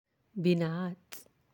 (binat)
binat.aac